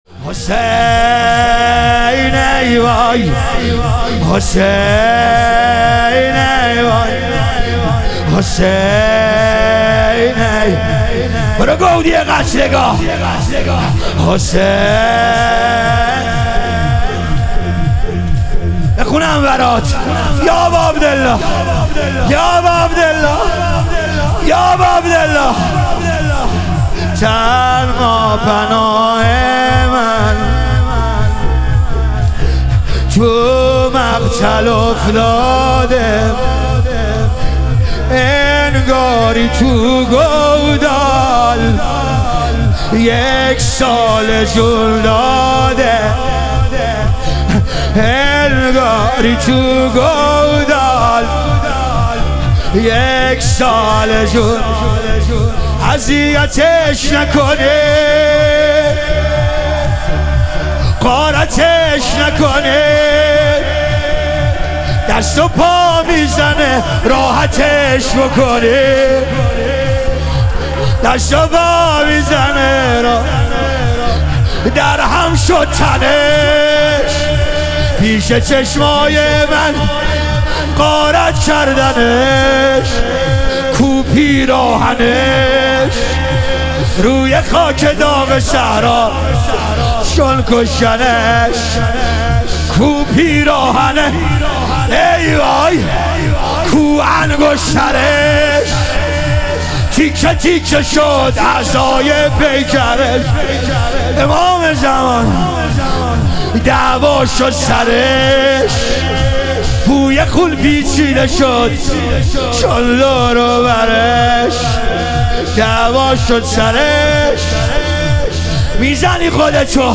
ایام فاطمیه دوم - لطمه زنی